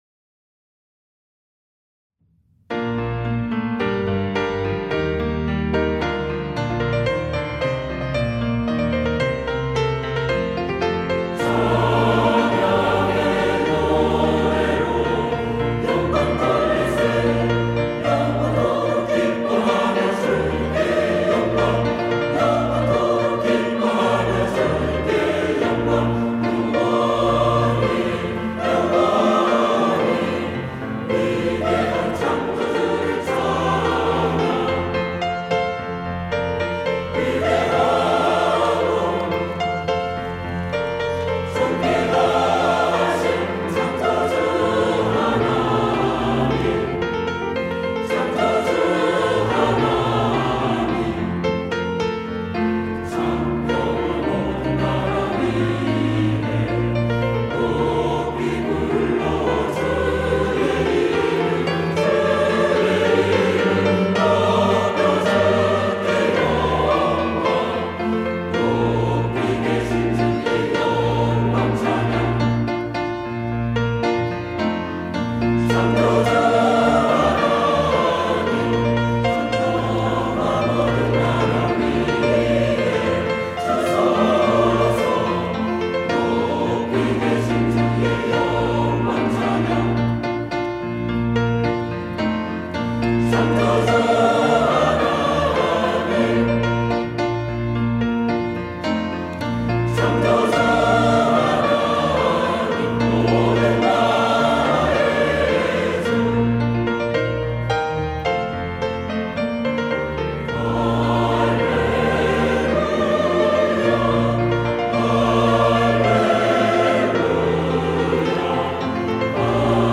시온(주일1부) - 창조주 하나님
찬양대 시온